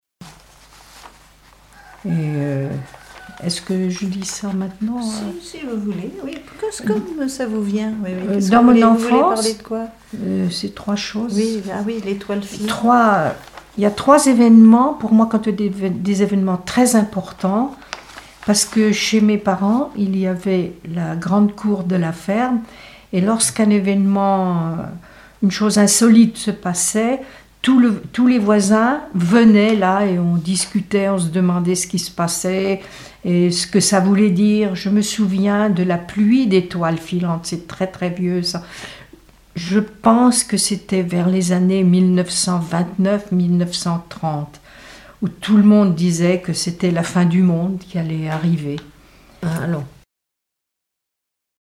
parole, oralité
Catégorie Témoignage